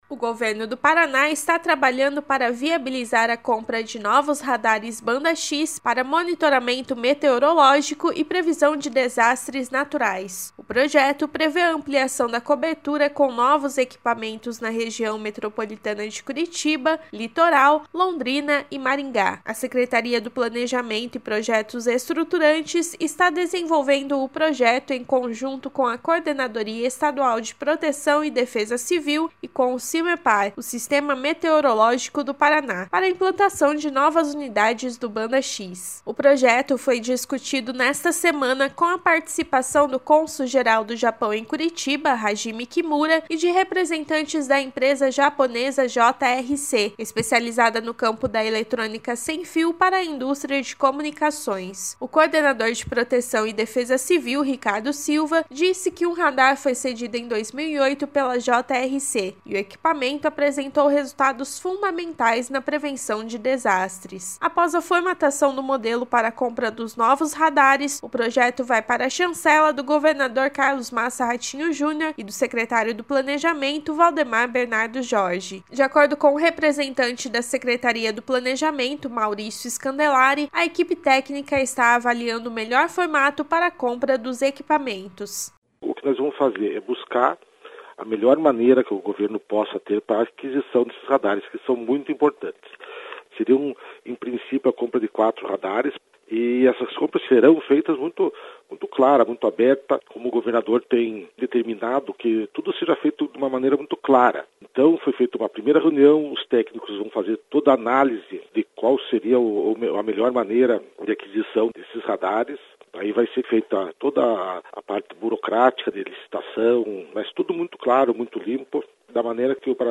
Essa e outras informações na programação da Rádio Cultura AM 930